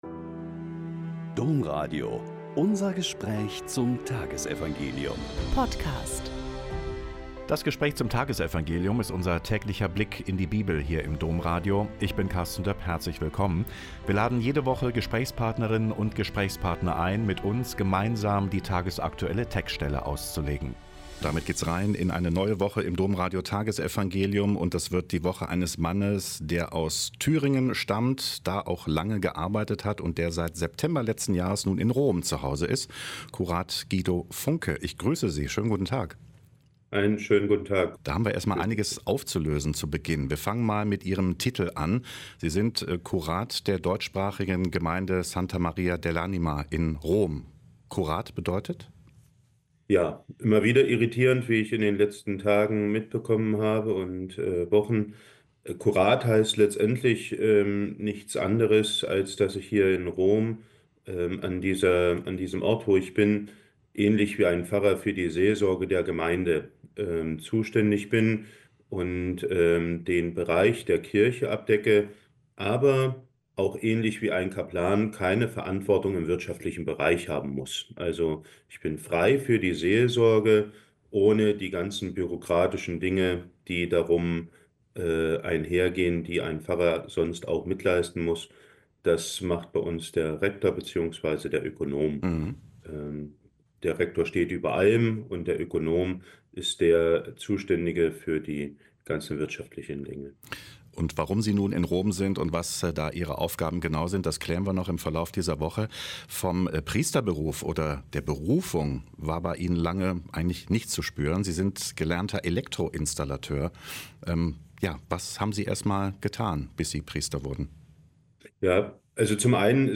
Mk 5,1-20 - Gespräch